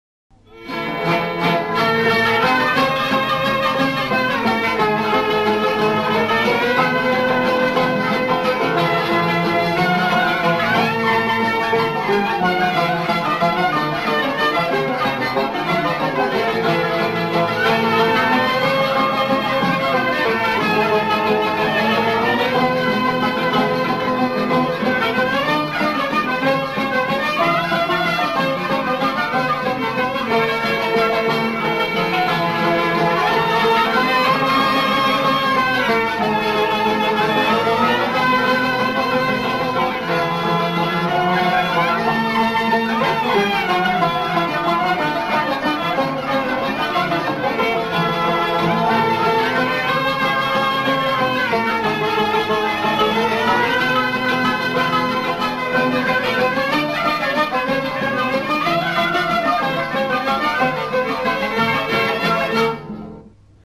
The last part of this Samaii is of three beat rhythm